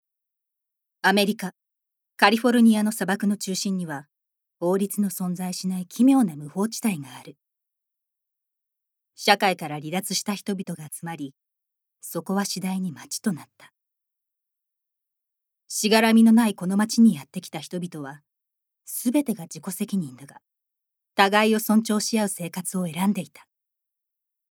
ボイスサンプル
ナレーション４